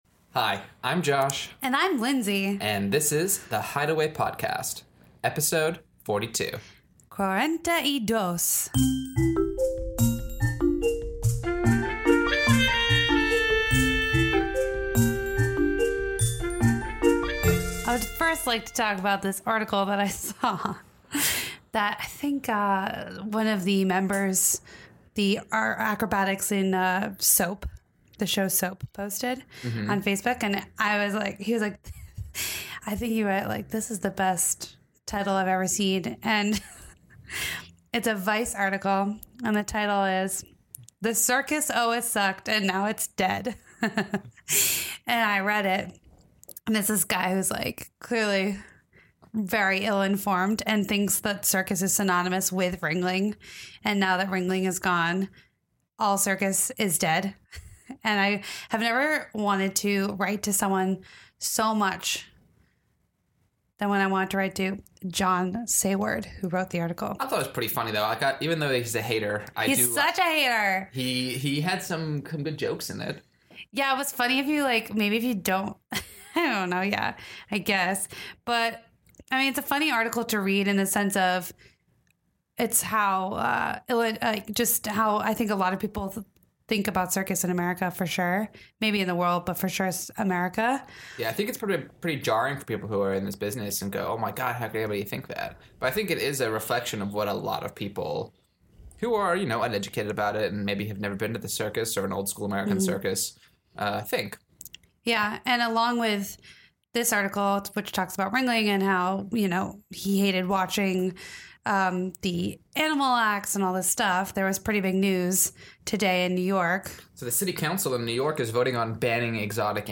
sit down for an interview